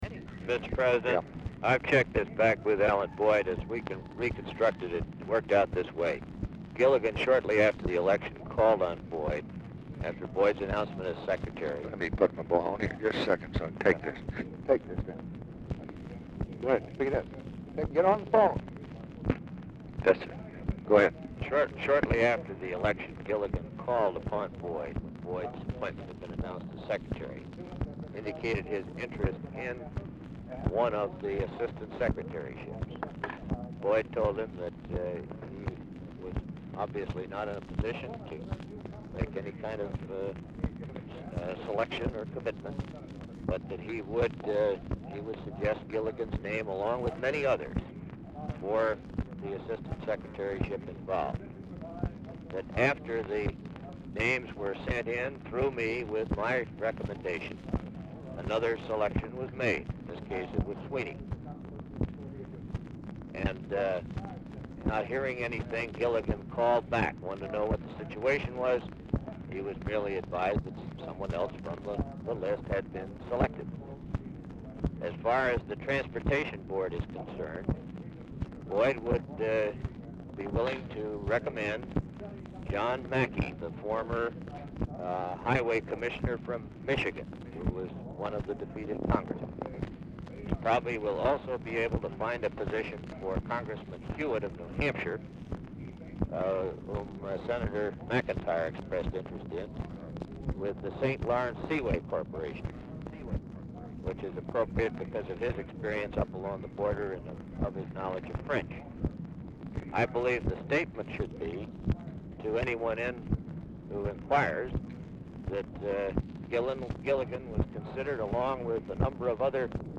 Telephone conversation # 11511, sound recording, LBJ and JOHN MACY, 2/3/1967, 10:41AM
TV OR RADIO AUDIBLE IN BACKGROUND
Dictation belt